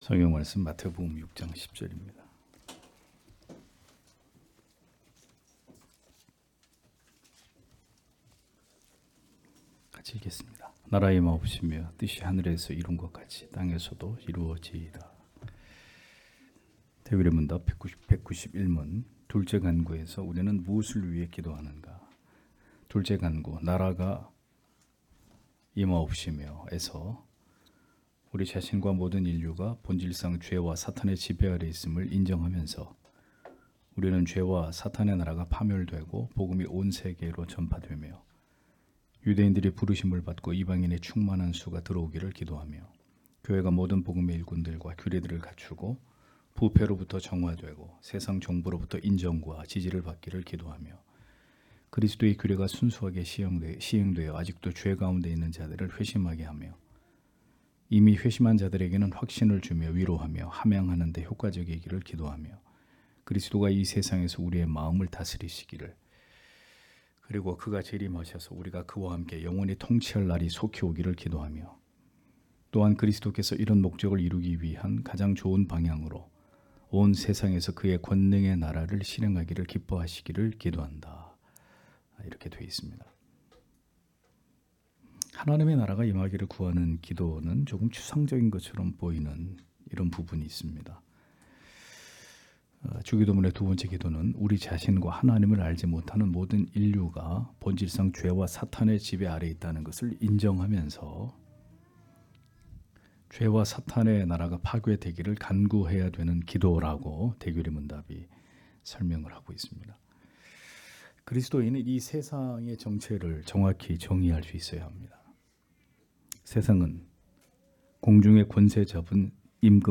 주일오후예배 - [웨스트민스터 대요리문답 해설 191] 191문) 둘째 기원에서 우리는 무엇을 기도하는가?
* 설교 파일을 다운 받으시려면 아래 설교 제목을 클릭해서 다운 받으시면 됩니다.